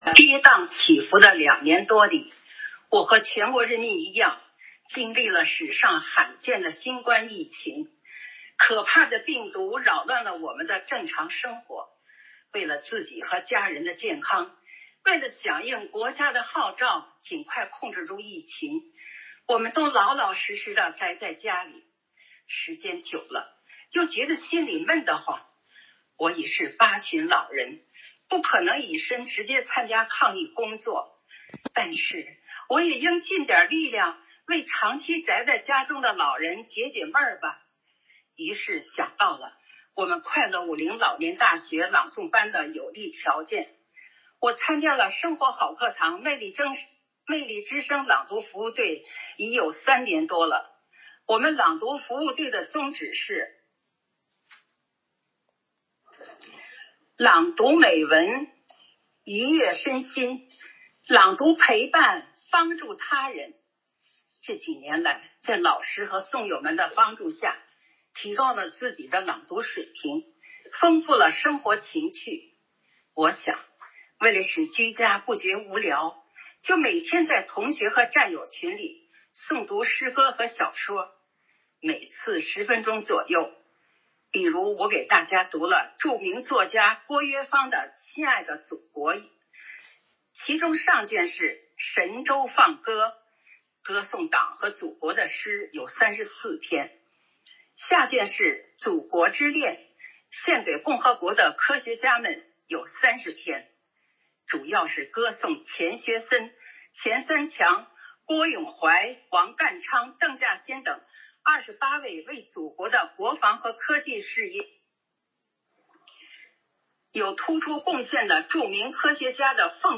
暨魅力之声支队第22场幸福志愿者朗诵会
《老有所学 老有所乐》原创独诵